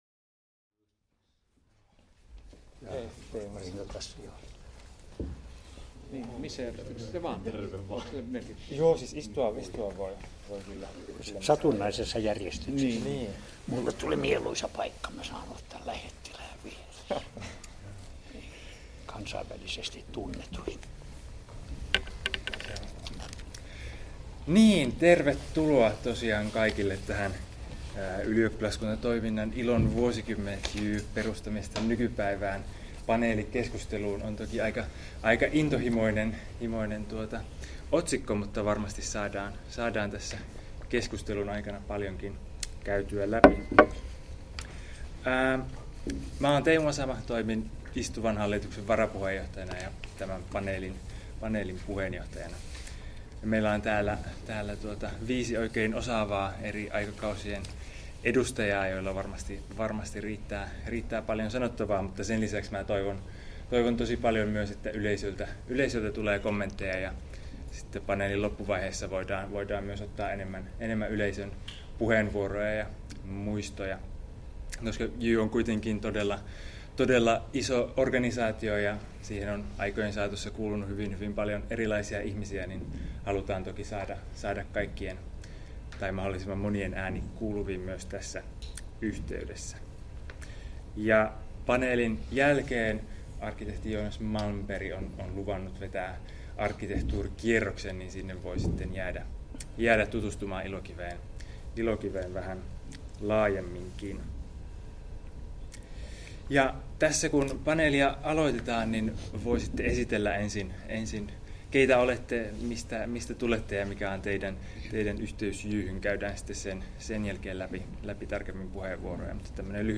Paneelikeskustelu, Ilokivi 14.9.2016 — Moniviestin
Ylioppilaskuntatoiminnan ilon vuosikymmenet - JYY perustamisesta nykypäivään -paneelikeskustelu jossa panelistit muistelevat ylioppilaskunnan vaiherikasta taivalta.